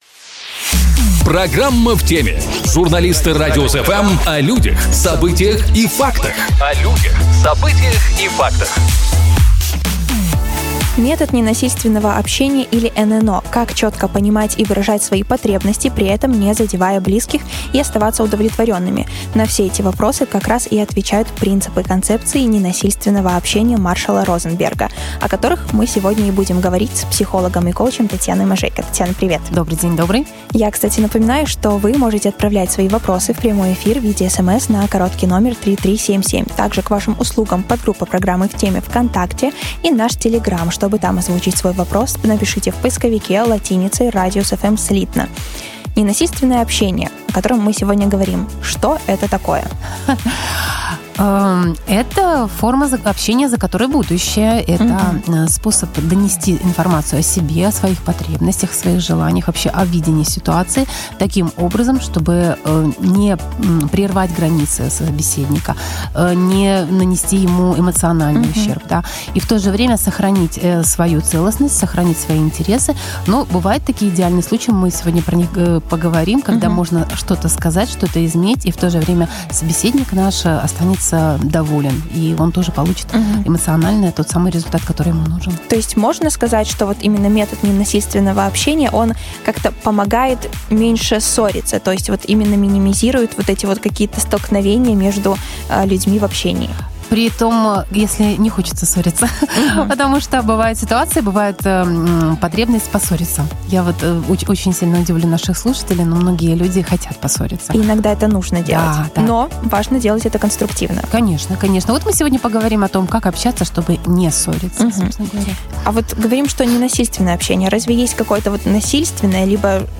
Сегодня говорим о том, как избежать непродуктивных дискуссий, быстро переходящих в обмен оскорблениями и общаться экологично. А в гостях у нас сегодня психолог и коуч